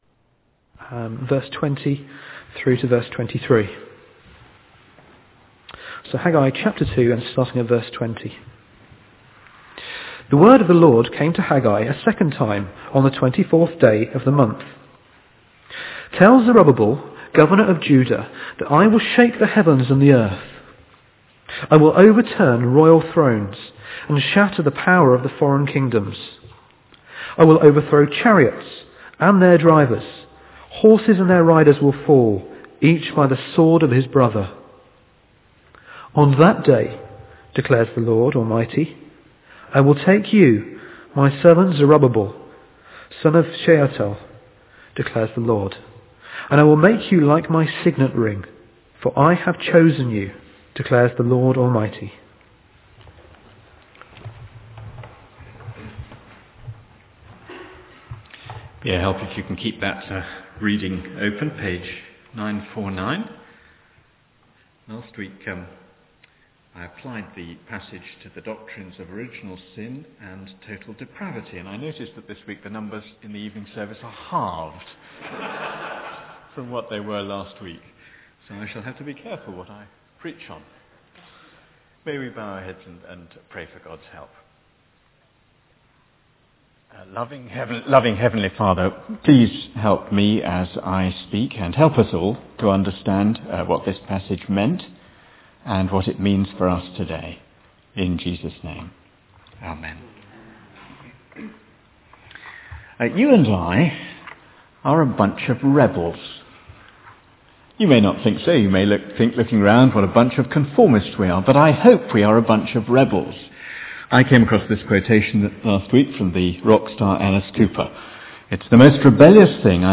This is a sermon on Haggai 2:20-23.